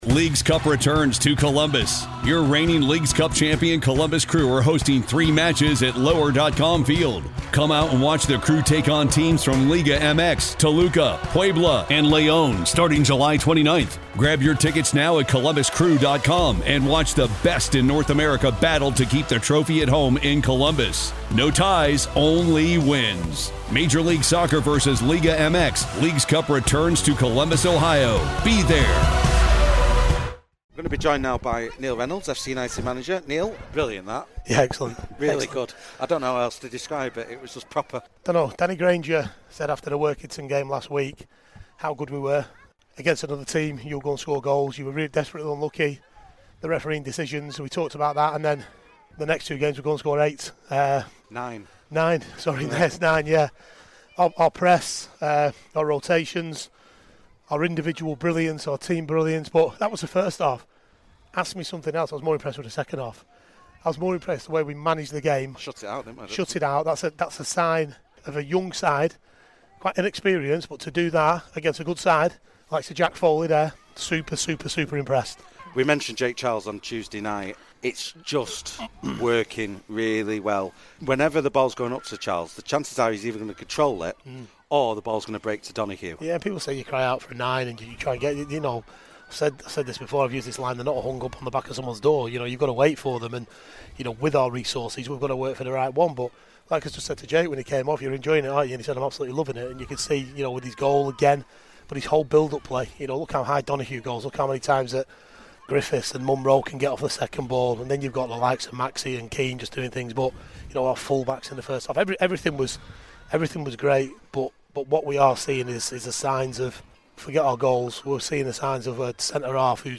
FCUM Radio / Post Match Interview